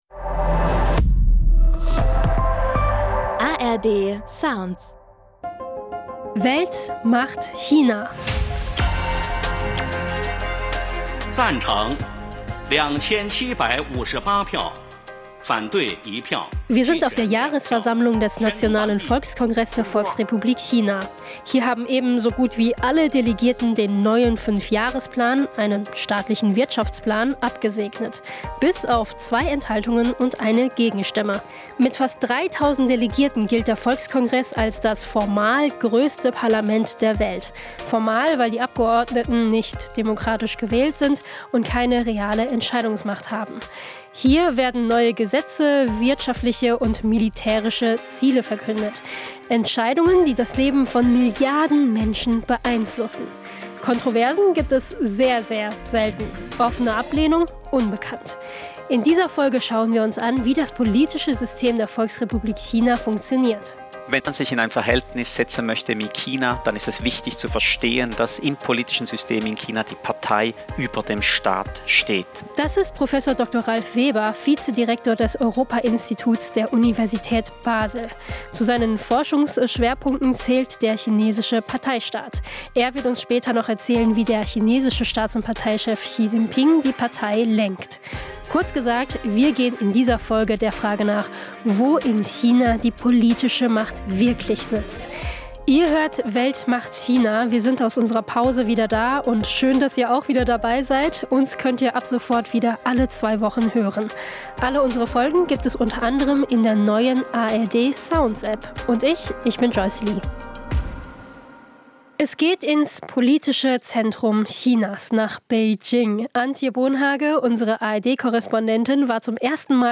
berichtet direkt vom Nationalen Volkskongress